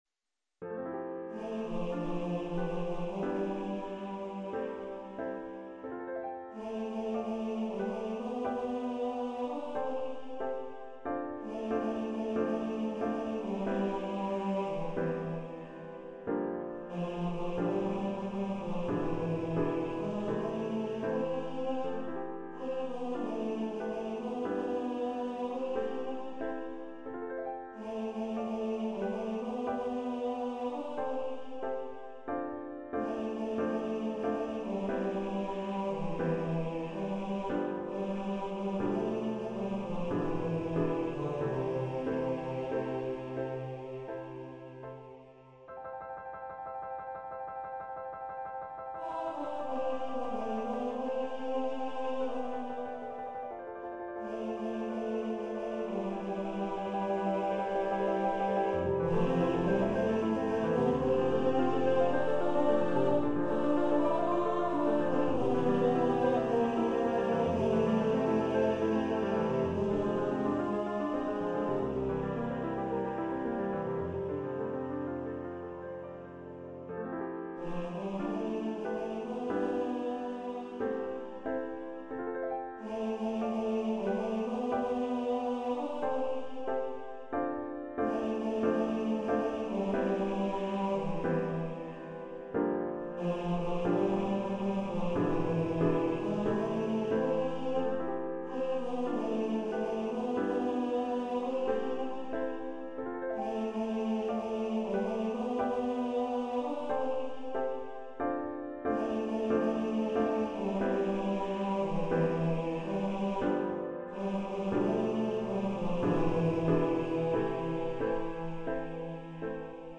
Baritone Voice and Piano
Composer's Demo